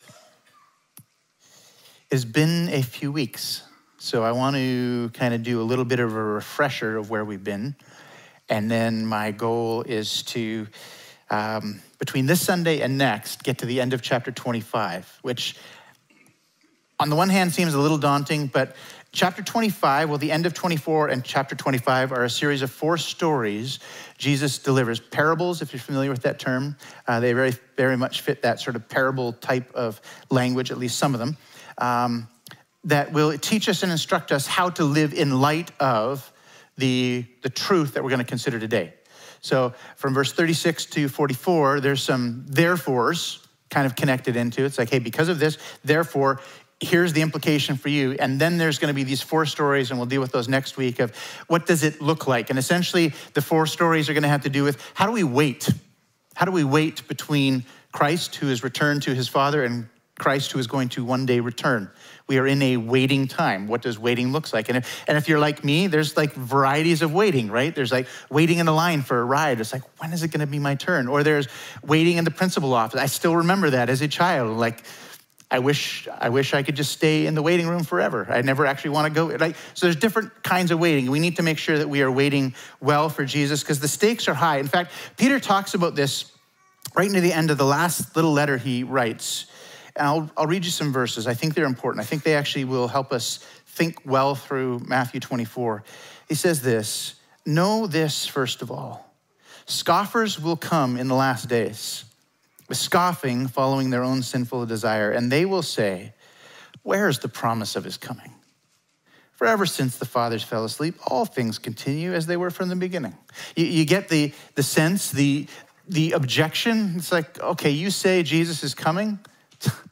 Sermons | Emmanuel Baptist Church